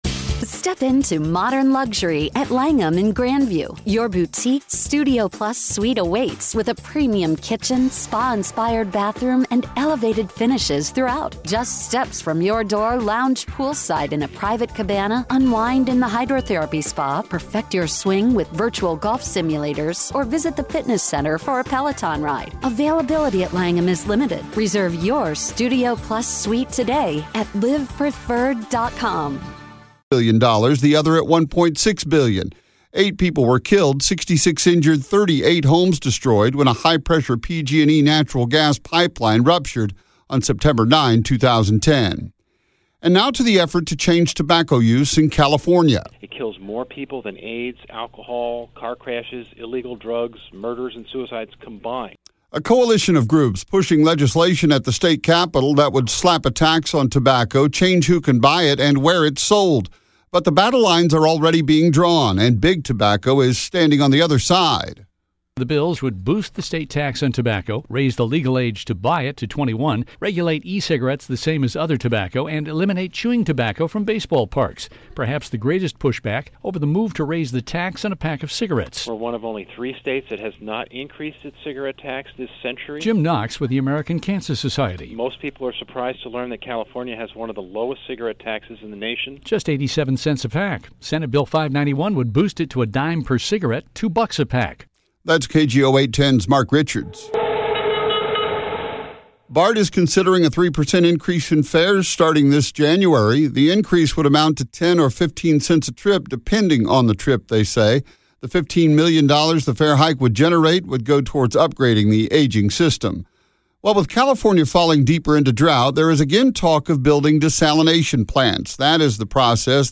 With dating guru, Matthew Hussey